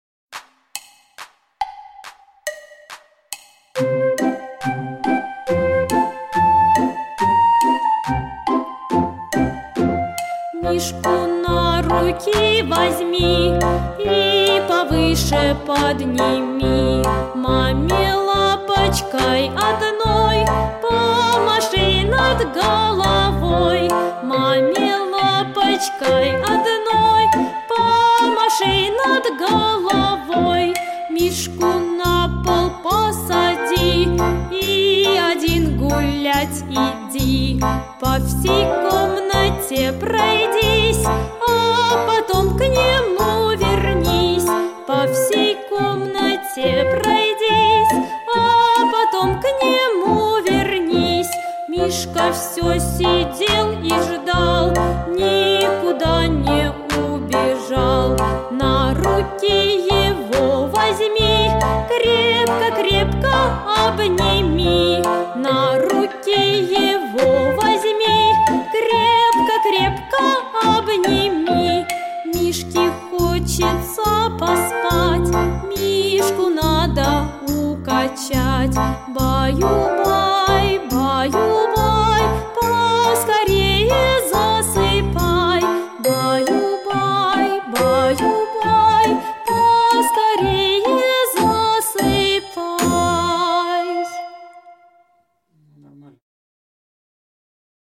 Современные, новые, популярные песни для детей 👶👧